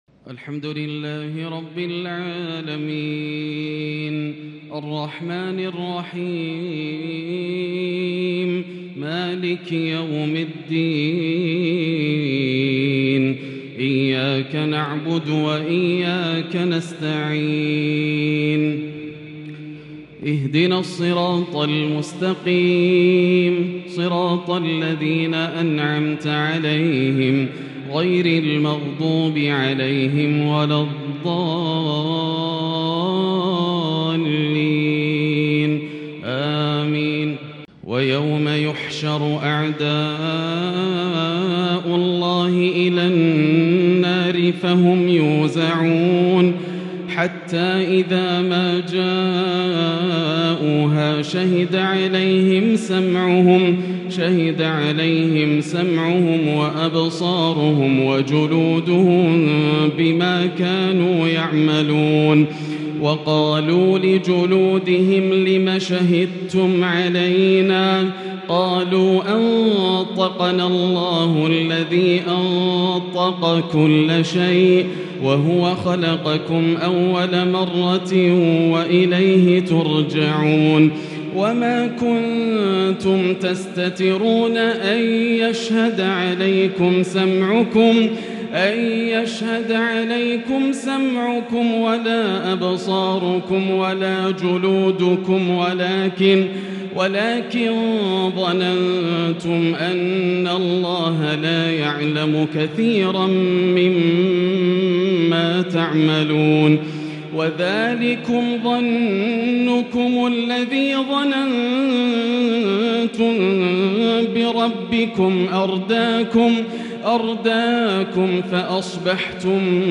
صلاة التهجد l ليلة 26 رمضان 1442 l من سورة فصلت (19) - سورة الشورى | tahajud prayer The 26rd night of Ramadan 1442H | from surah Fussilat and Ash-Shura > تراويح الحرم المكي عام 1442 🕋 > التراويح - تلاوات الحرمين